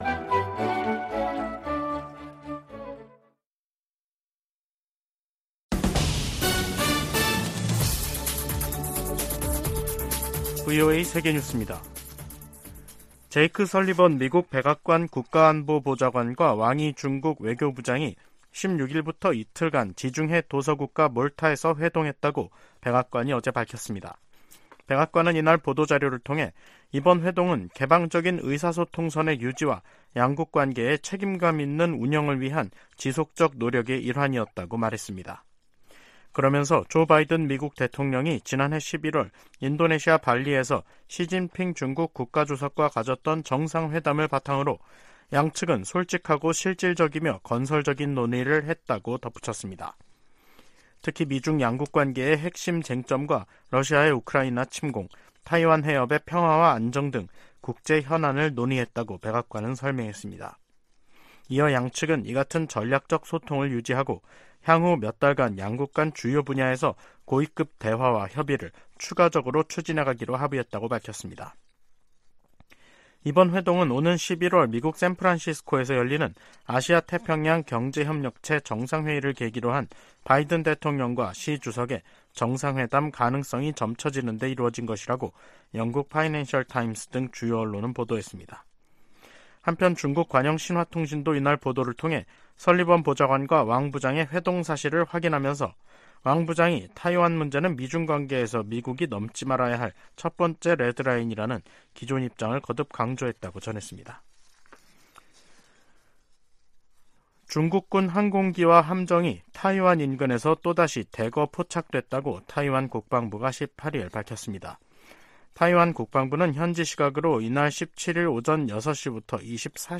VOA 한국어 간판 뉴스 프로그램 '뉴스 투데이', 2023년 9월 18일 2부 방송입니다. 백악관은 북한과 러시아 사이에 무기 제공 논의가 계속 진전되고 있으며 예의 주시하고 있다고 밝혔습니다. 윤석열 한국 대통령은 북-러 군사협력 움직임에 대해 유엔 안보리 결의에 반한다며 한반도 문제 해결을 위한 중국의 역할을 거듭 촉구했습니다. 우크라이나 주변국들은 북한이 러시아에 우크라이나 전쟁에 필요한 무기를 제공 중이라는 주장에 촉각을 곤두세우고 있습니다.